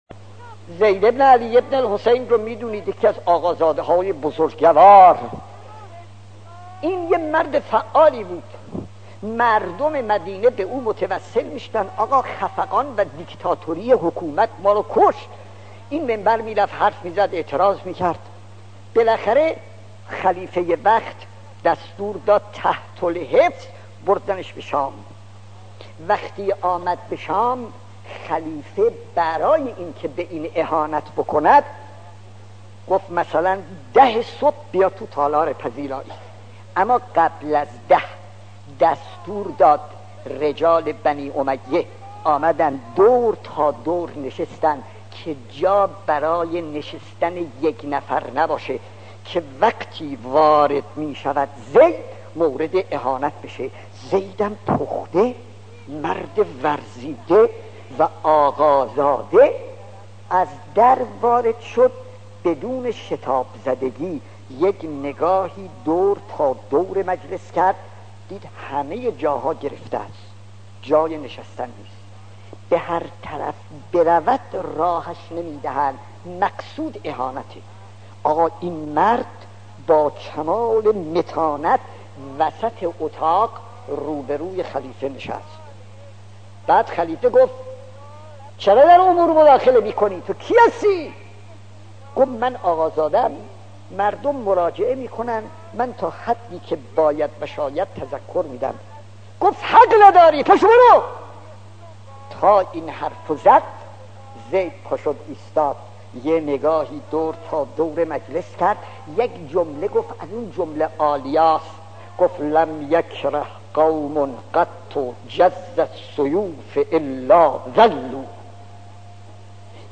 داستان 22 : زید ابن علی ابن الحسین خطیب: استاد فلسفی مدت زمان: 00:02:23